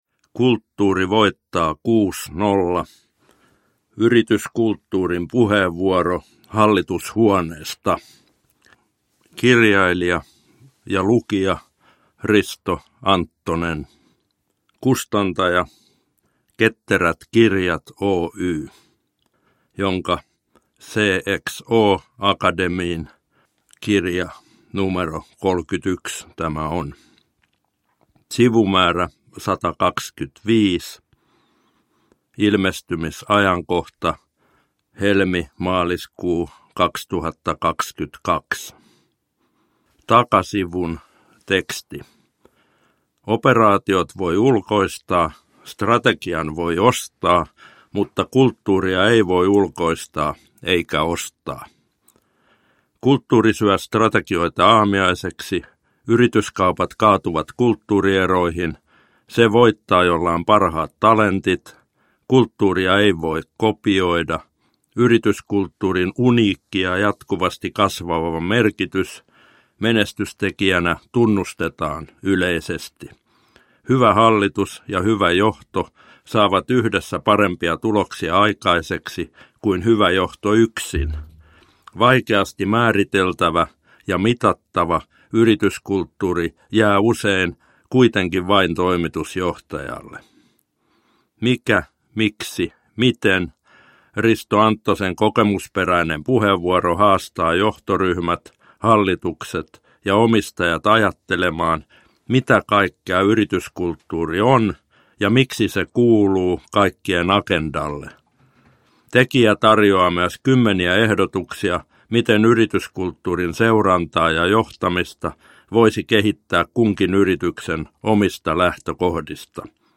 Kulttuuri voittaa kuusnolla – Ljudbok – Laddas ner